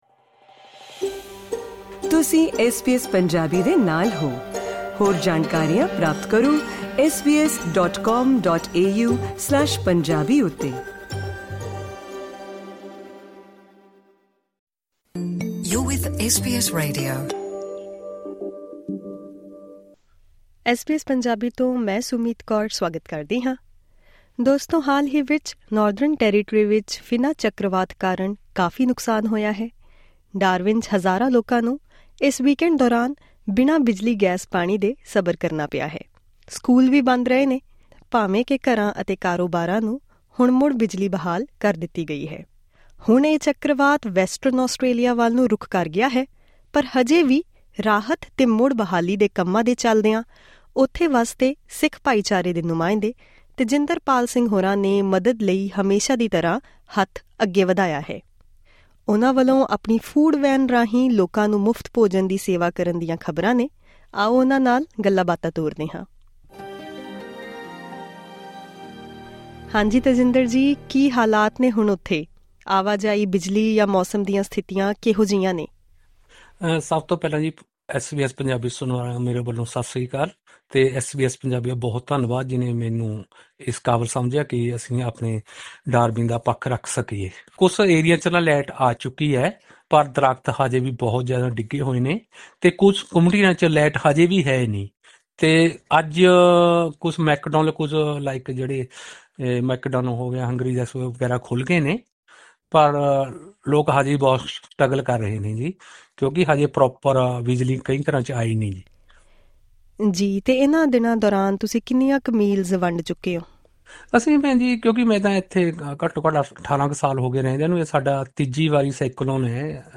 ਪੂਰੀ ਗੱਲਬਾਤ ਇਸ ਪੌਡਕਾਸਟ ਰਾਹੀਂ ਸੁਣੋ...